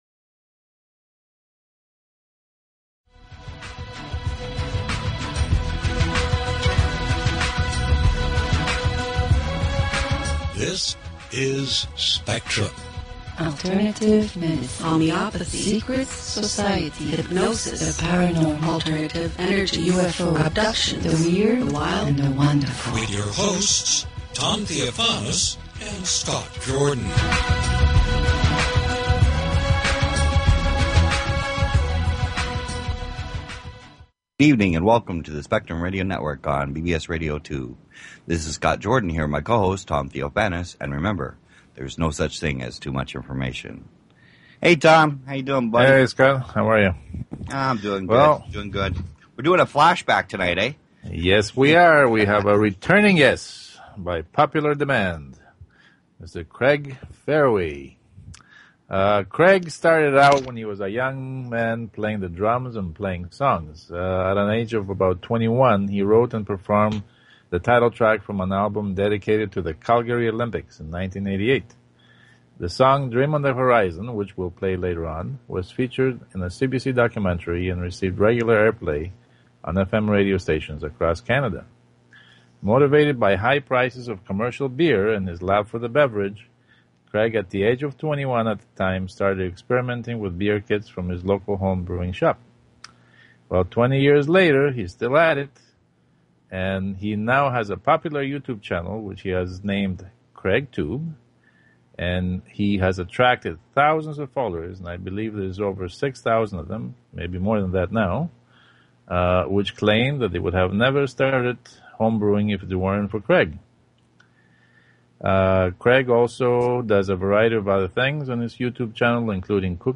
Talk Show Episode, Audio Podcast, Spectrum_Radio_Network and Courtesy of BBS Radio on , show guests , about , categorized as
We then had a very interesting discussion about the recording industry. It was a very enjoyable evening with lots of interesting discussions.